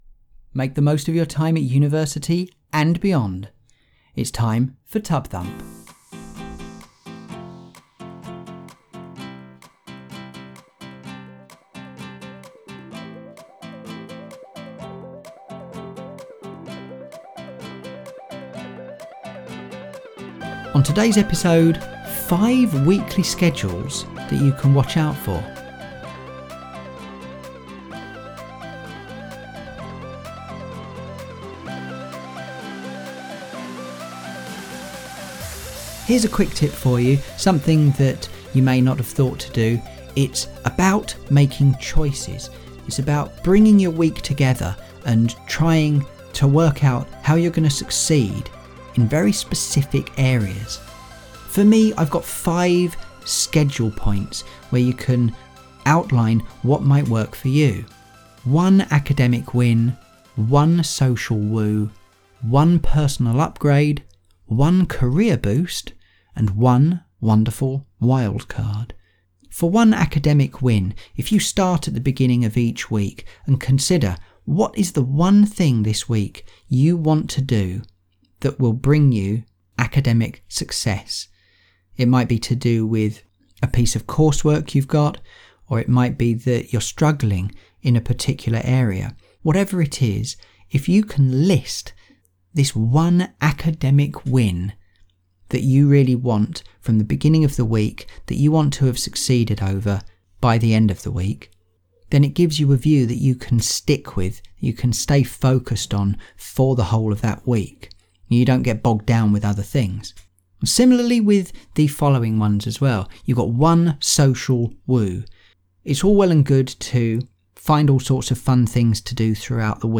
Music for TUB-Thump is Life, by Tobu, which is released under a Creative Commons license.